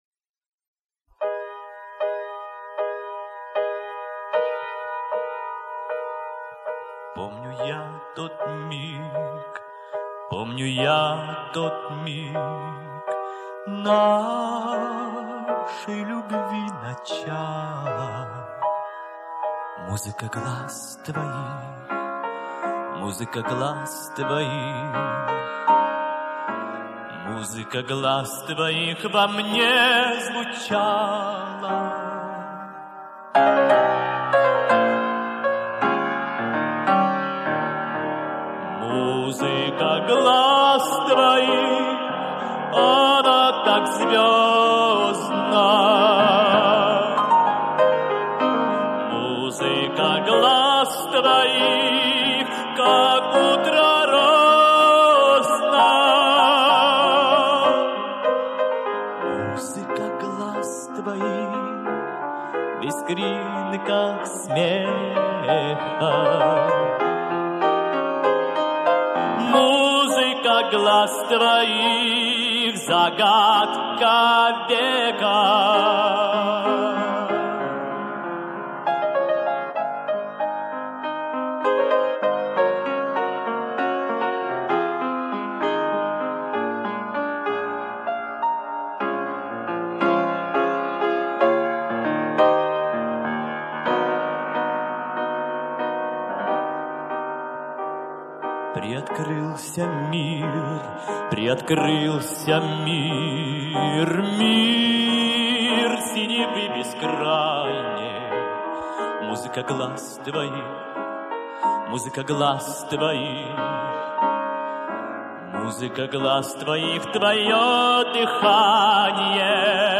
А во время одной из репетиций, кажется в Кишинёве, мы сделали рабочую запись - я играю, он поёт...